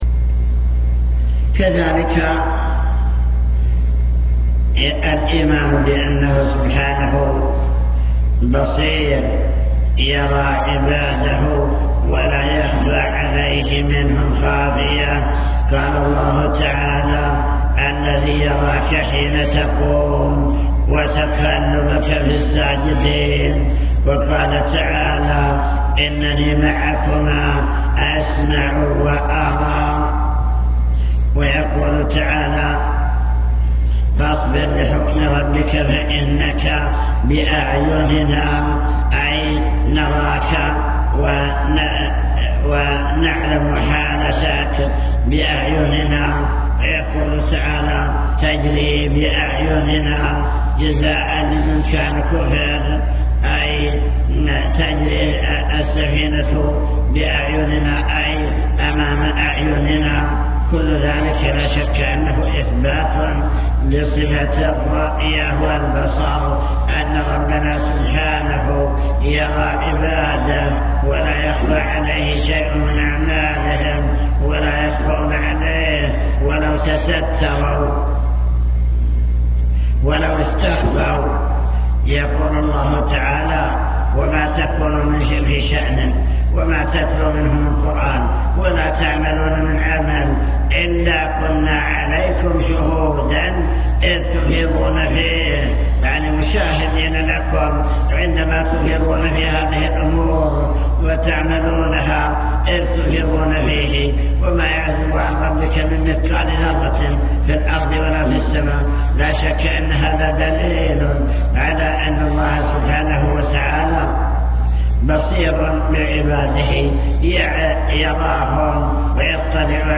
المكتبة الصوتية  تسجيلات - محاضرات ودروس  محاضرة الإيمان باليوم الآخر